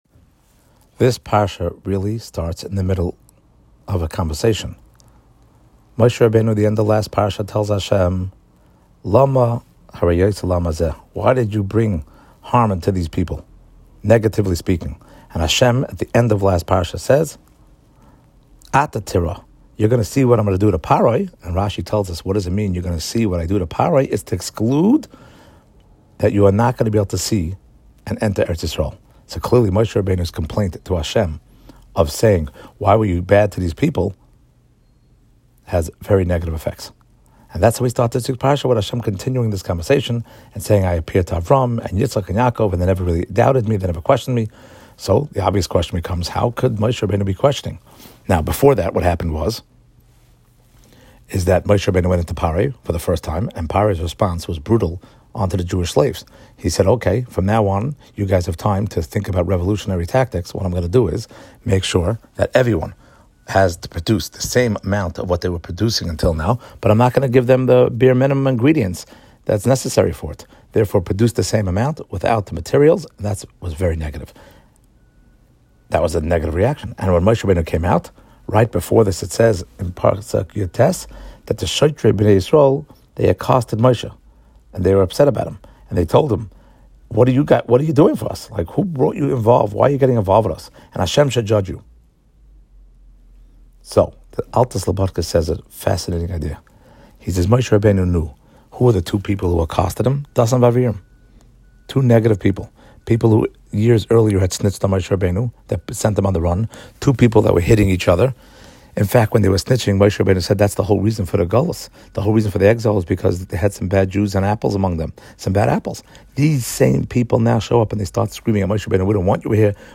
Short Shiur on Weekly Parsha